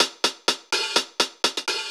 Index of /musicradar/ultimate-hihat-samples/125bpm
UHH_AcoustiHatA_125-01.wav